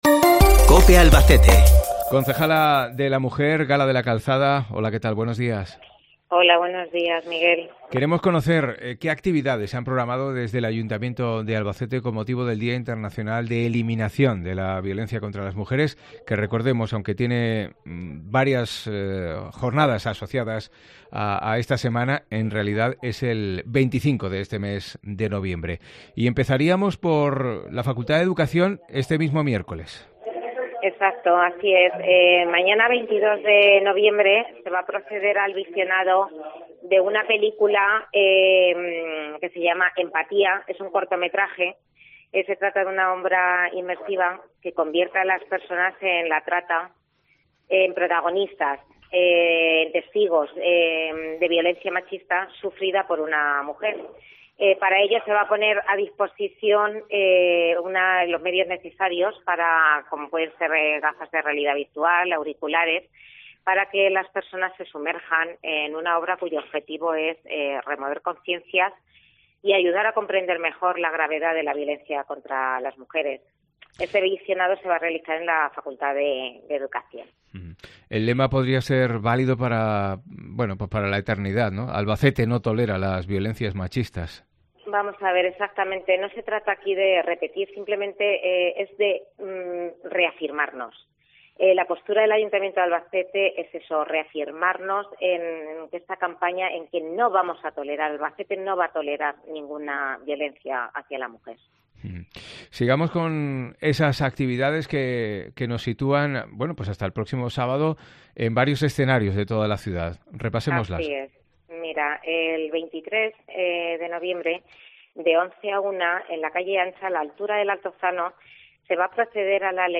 La concejala de la Mujer del Ayuntamiento de Albacete relata las actividades que se han programado con motivo del 25 de noviembre